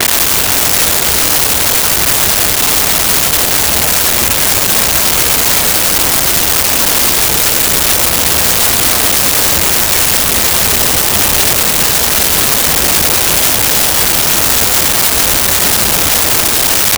Train Rail Clacks Short
Train Rail Clacks Short.wav